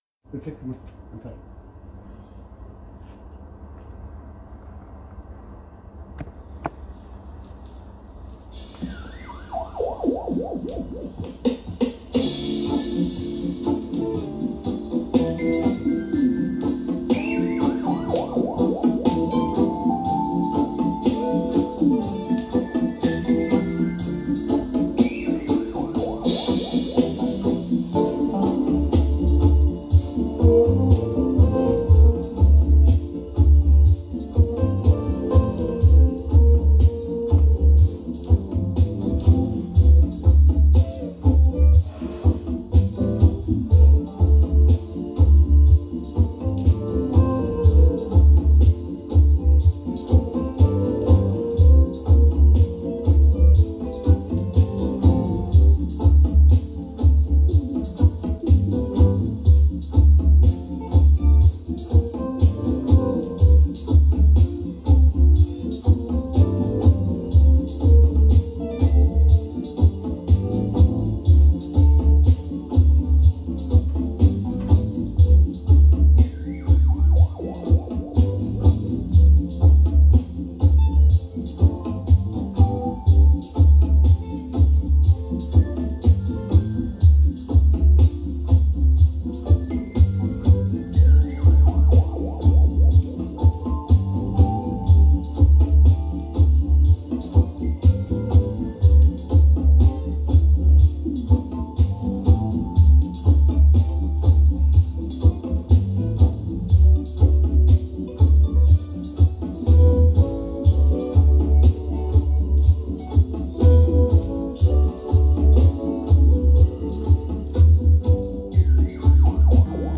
There were some gaps which I edited together.